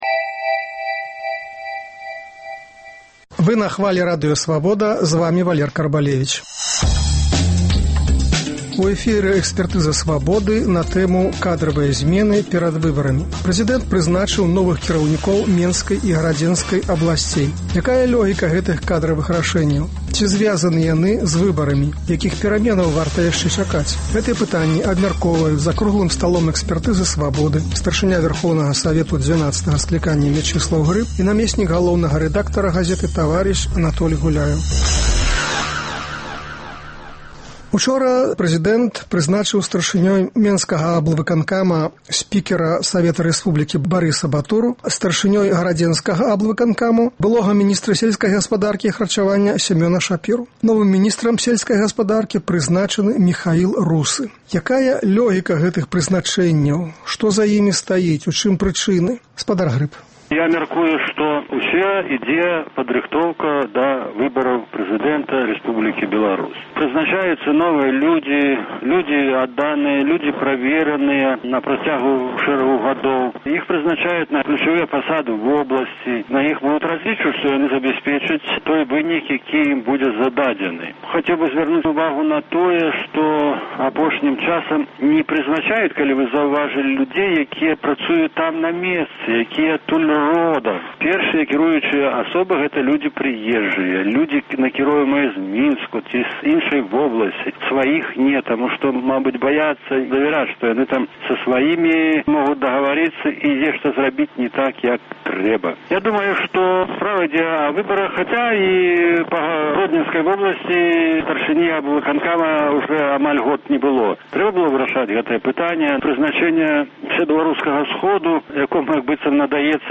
Гэтыя пытаньні абмяркоўваюць за круглым сталом